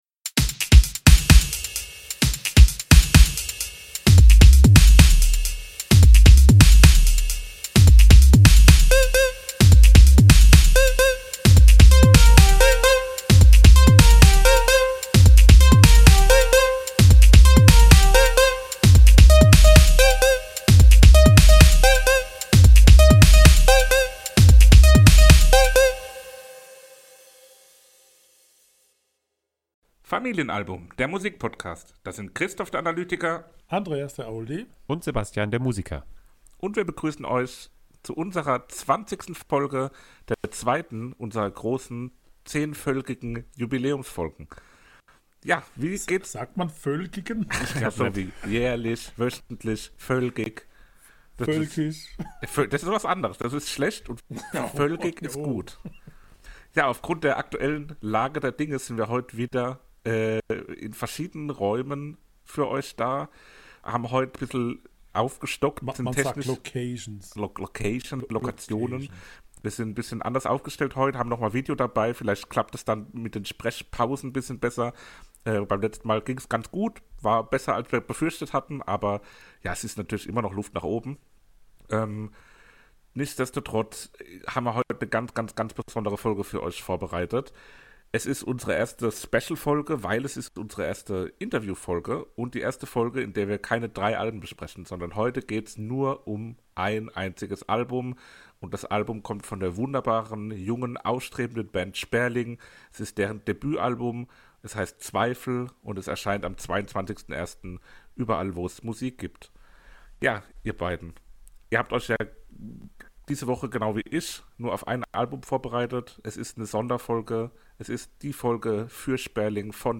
Folge 20 Interview / Review zum Album „Zweifel“ von Sperling